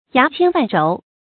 牙簽萬軸 注音： ㄧㄚˊ ㄑㄧㄢ ㄨㄢˋ ㄓㄡˊ 讀音讀法： 意思解釋： 形容藏書非常多。